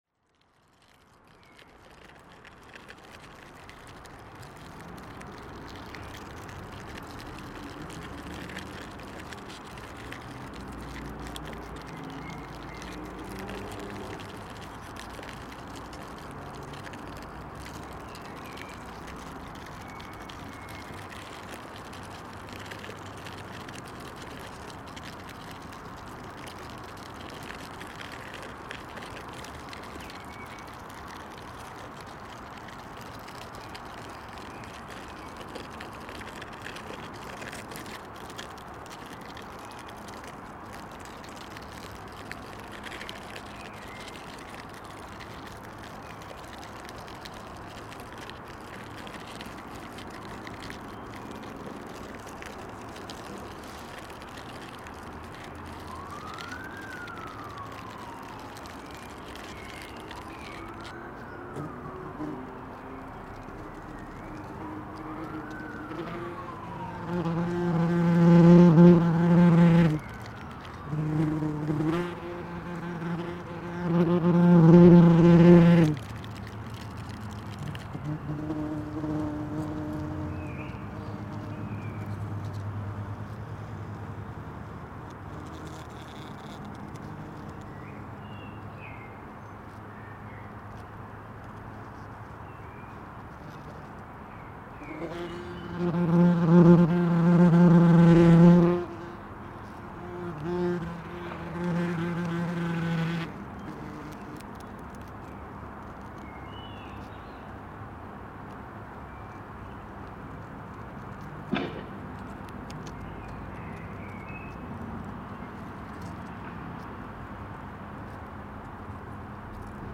Á meðan ég lét tímann líða ákvað ég að taka upp inni í fuglaskoðunarhúsinu. Reglulega mátti heyra þrusk í starra sem var við hreiðurgerð í þakskegginu, nokkuð seint en líklega vegna ótíðar. Hér heyrist helst í vindi lemja kofann, í mófuglum og einmanna kind.
Posted in Náttúra, tagged Friðland í Flóa, Korg MR1000, Rode NT1a, Rok, Sauðkind, Sound devices 552, Starri, Veður on 9.7.2011| 2 Comments »